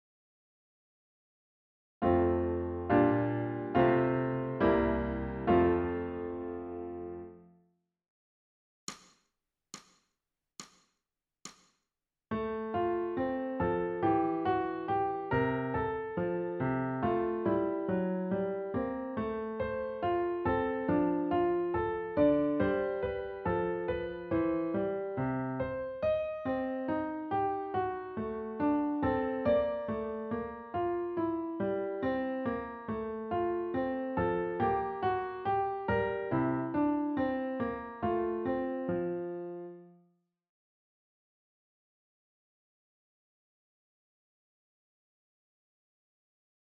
ソルフェージュ 聴音: 2-2-05